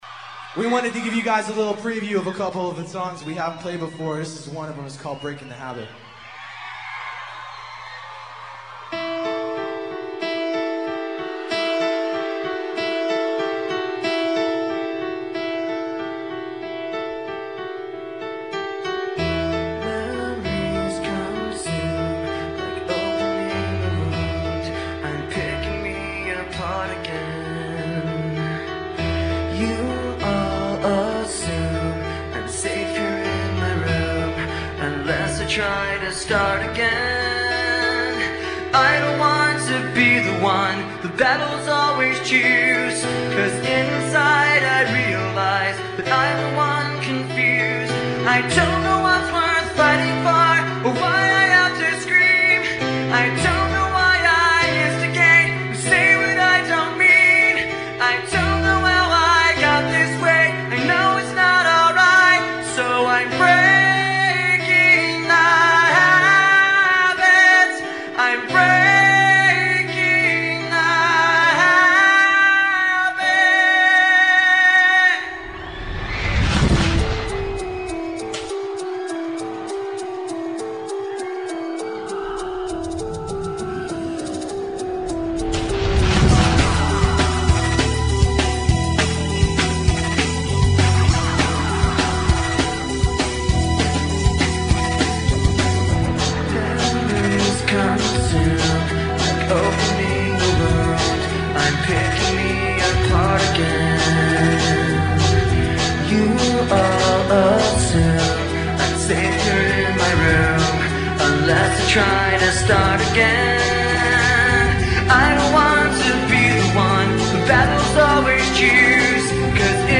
Nu-Metal